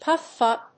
púff úp